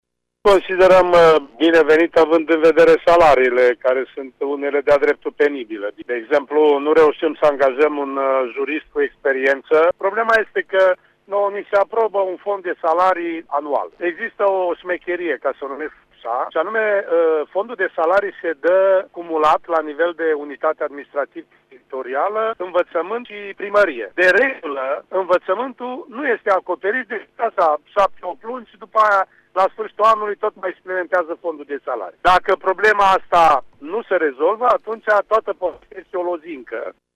Primarul municipiului Topliţa, Stelu Platon, crede ca fondurile de salarizare aprobate sunt prea mici şi, dacă nu vor creşte, măsura aprobată ieri rămâne o lozincă: